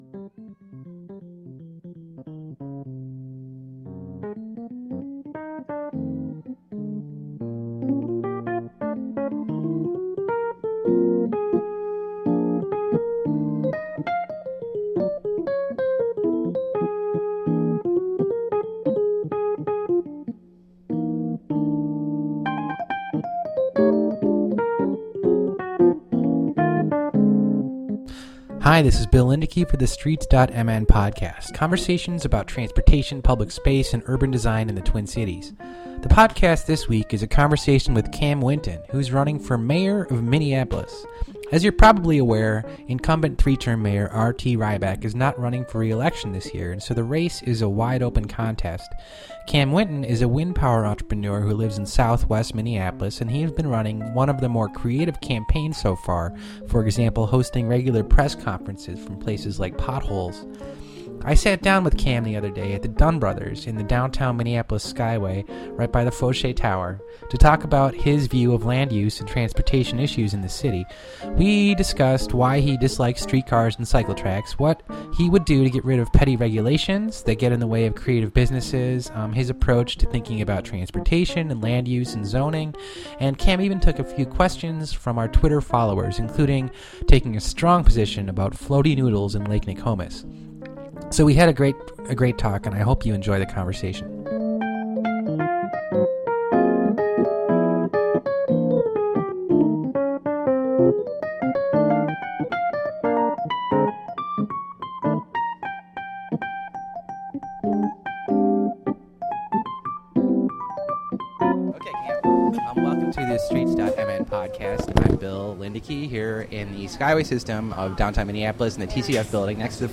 I hope you enjoy the conversation.